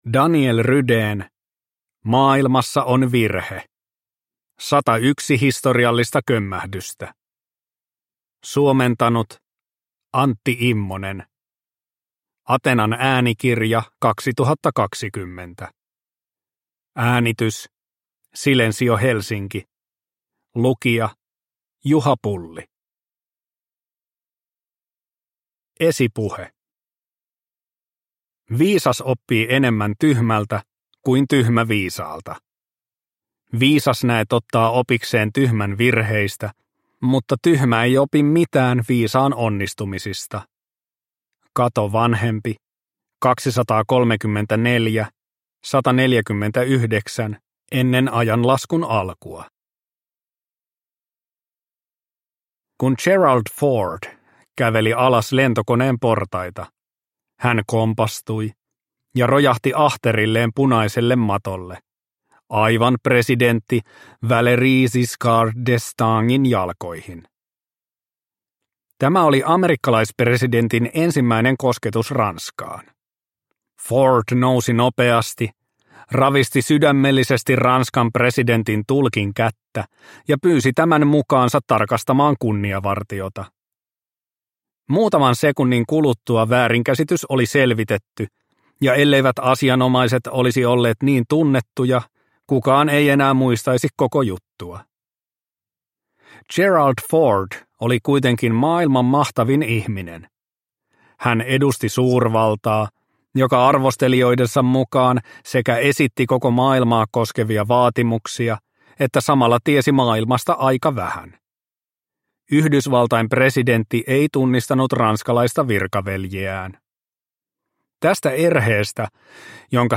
Maailmassa on virhe – Ljudbok – Laddas ner